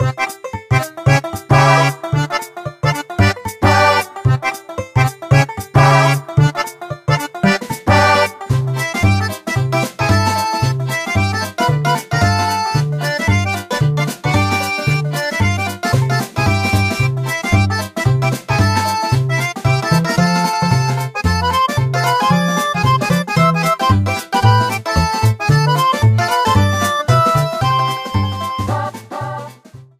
Directly ripped from the ISO
Faded in the end